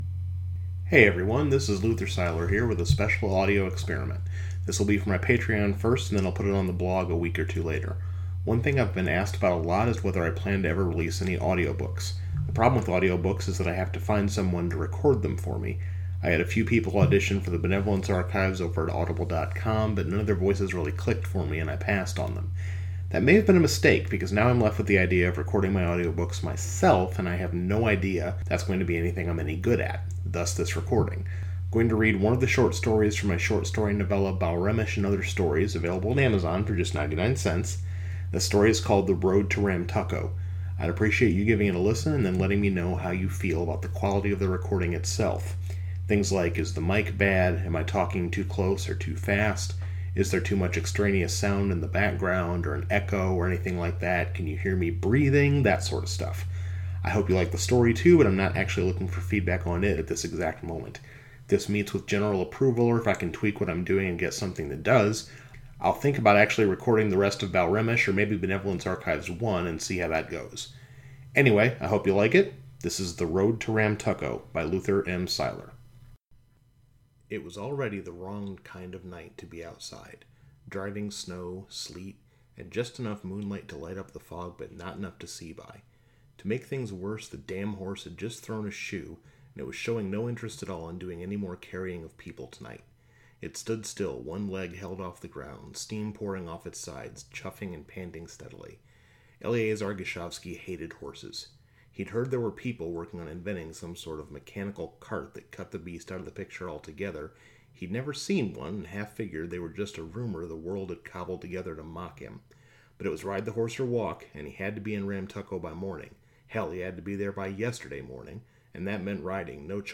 (One thing, tho: there is going to be a volume difference between the introduction and the actual story.